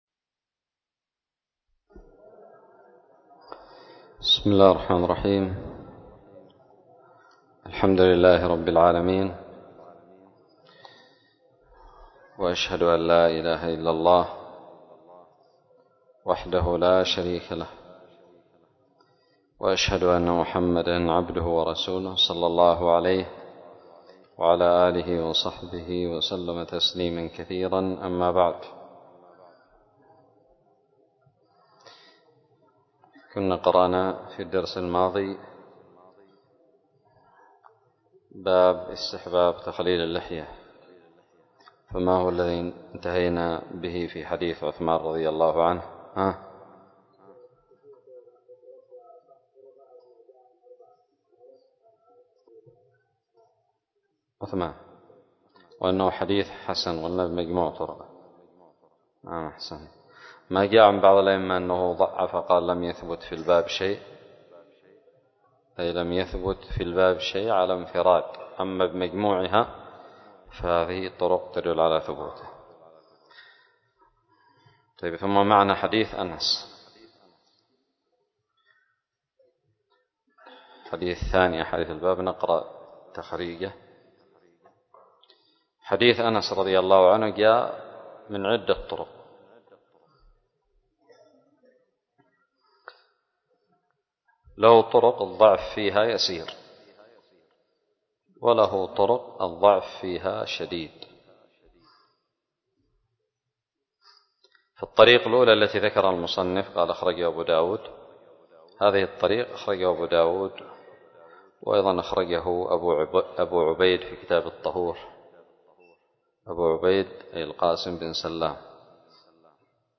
الدرس الثالث عشر بعد المائة من كتاب الطهارة من كتاب المنتقى للمجد ابن تيمية
ألقيت بدار الحديث السلفية للعلوم الشرعية بالضالع